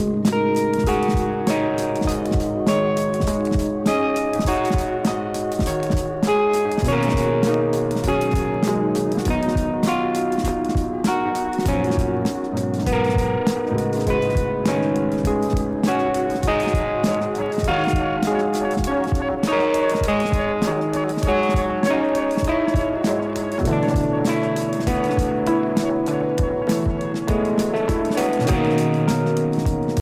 パート1となるこちらはギター、キーボード、ドラム、エレクトロニクスが巧みに絡み合う全7曲を収録。